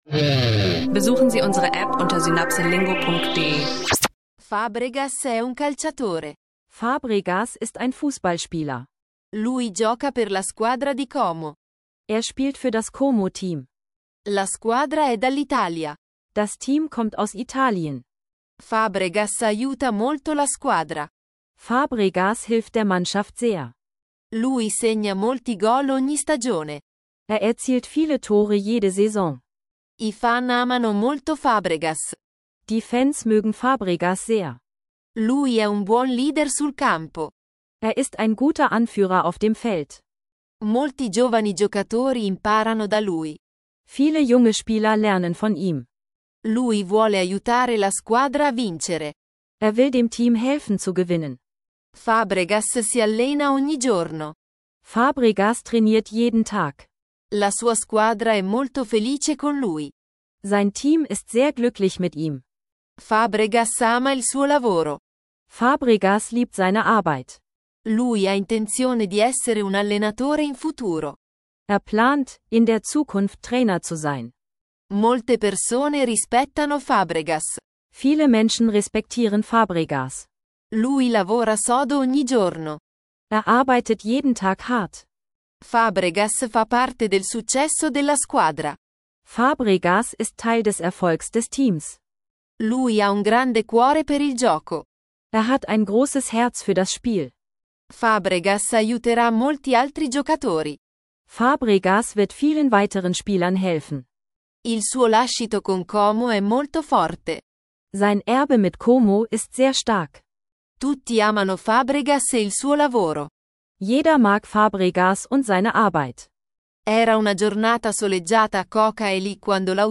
In dieser Folge lernst du Italienisch mit einem KI-unterstützten Audio Sprachkurs, der dich auf spannende Geschichten wie die von Fábregas und seinem Erbe in Como mitnimmt.